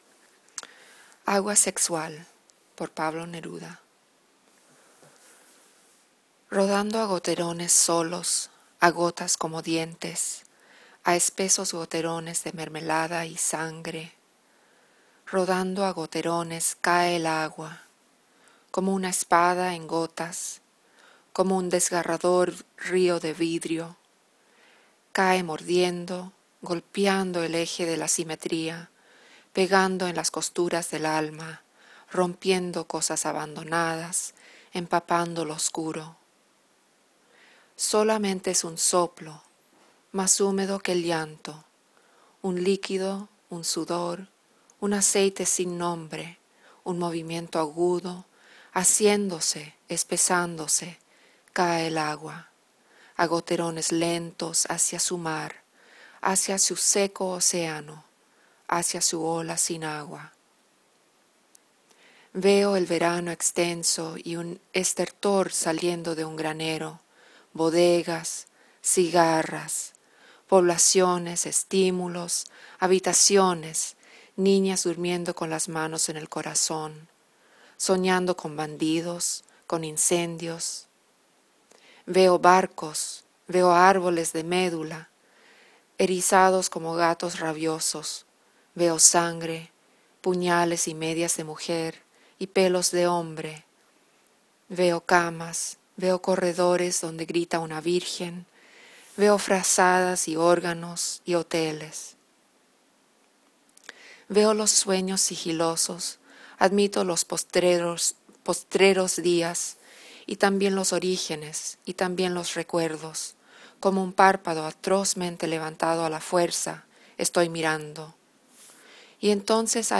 agua-sexual-pablo-neruda.m4a